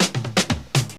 Понятно, что что-то из фанка 70-х-начала 80-х Вложения BREAK 116.wav BREAK 116.wav 86,2 KB · Просмотры: 154